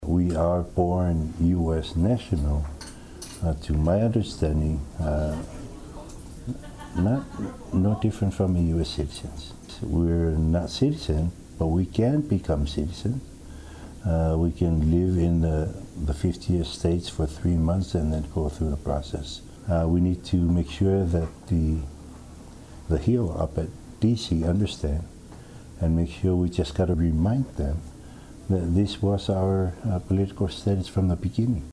Governor Lemanu Peleti Mauga speaking to Hawaii News Now about American Samoans’ status as US nationals.